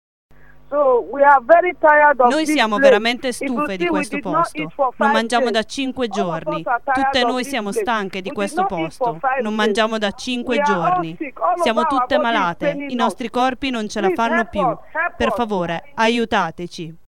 Le detenute hanno iniziato da cinque giorni uno sciopero della fame: sono stanche e non ce la fanno più. Ascolta la testimonianza.